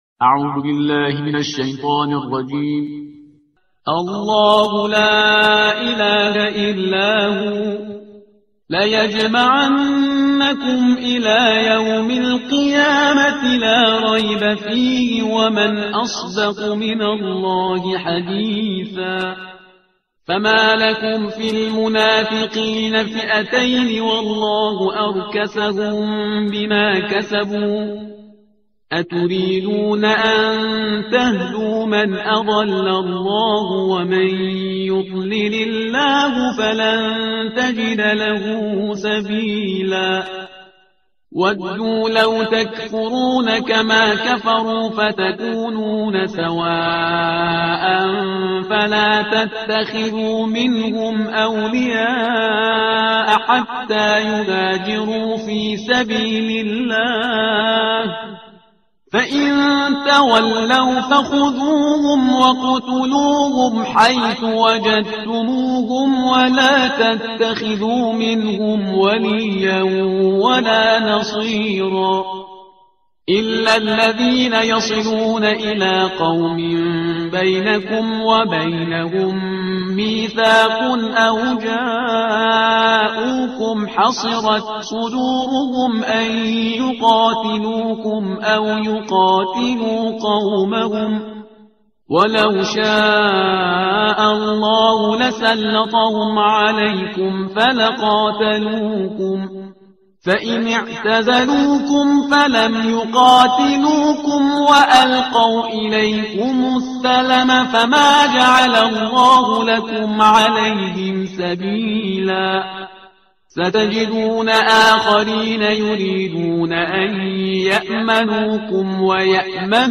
ترتیل صفحه 92 قرآن